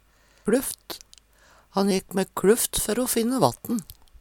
kLufft - Numedalsmål (en-US)